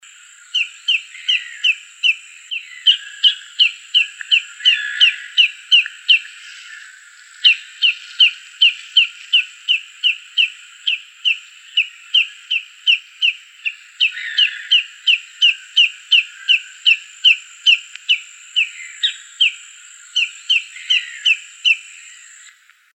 Maçarico-de-perna-amarela (Tringa flavipes)
Nome em Inglês: Lesser Yellowlegs
Fase da vida: Adulto
Localidade ou área protegida: Reserva Ecológica Costanera Sur (RECS)
Condição: Selvagem
Certeza: Gravado Vocal